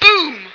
flak_m/sounds/female1/int/F1boom.ogg at ac4c53b3efc011c6eda803d9c1f26cd622afffce